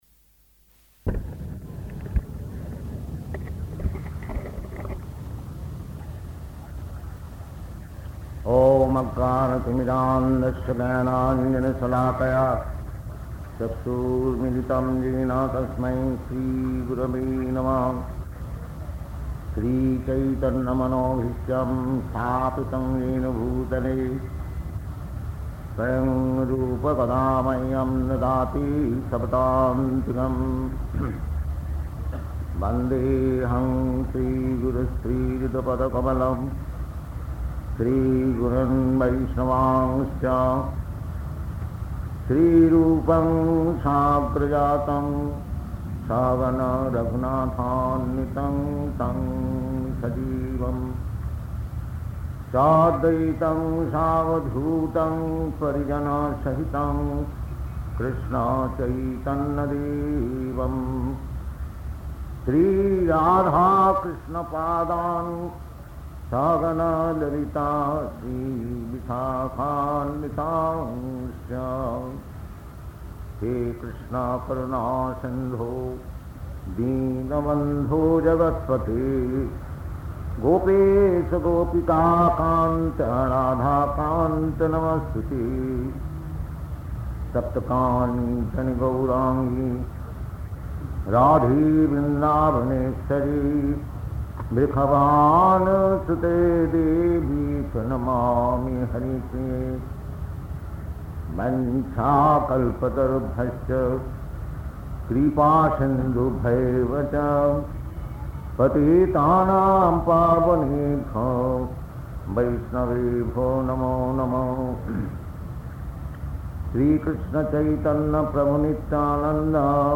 Paṇḍāl Lecture
Type: Lectures and Addresses
Location: Bombay